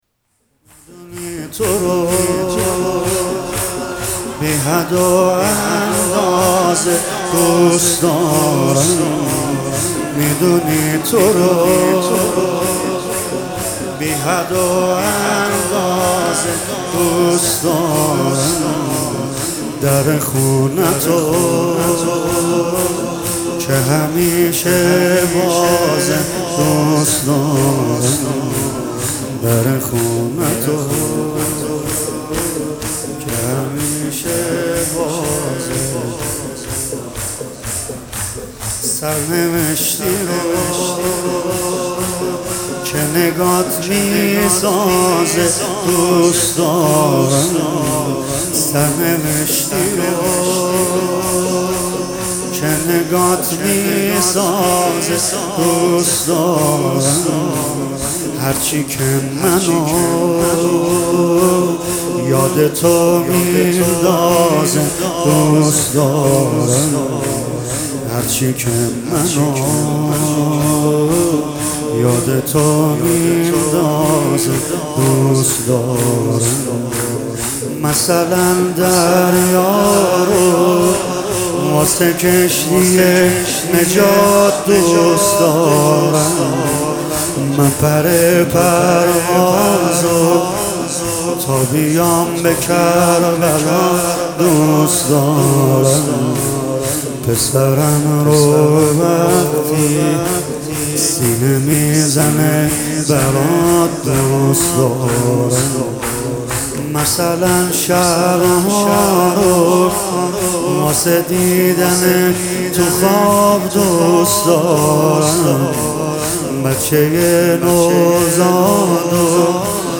هیئت فدائیان حسین (ع) اصفهان